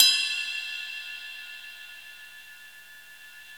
CYM XRIDE 1C.wav